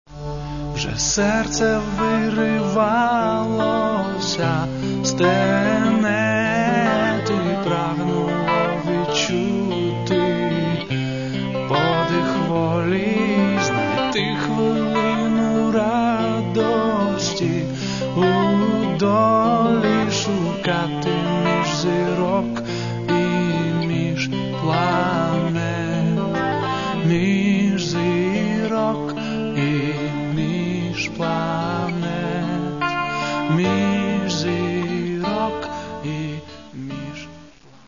Каталог -> Поп (Легка) -> Збірки